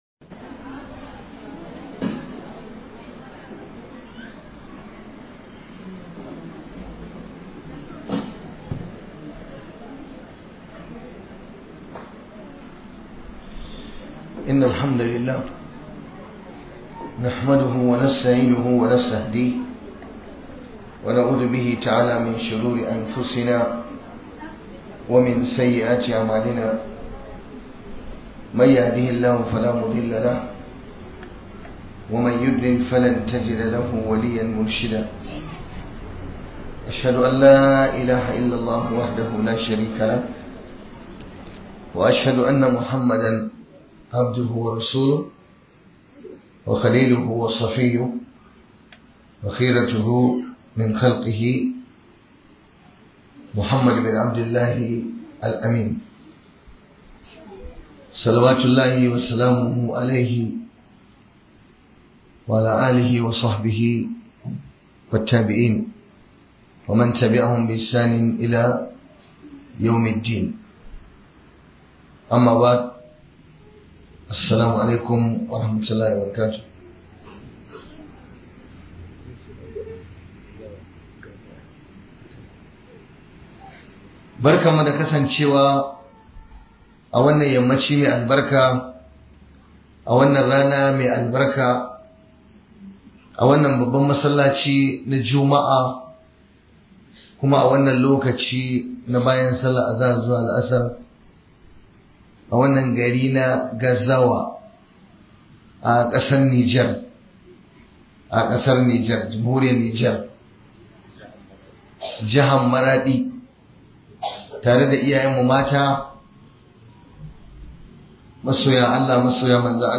TUBA DA KOMAWA GA ALLAH. - MUHADARA